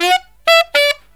63SAXMD 05-L.wav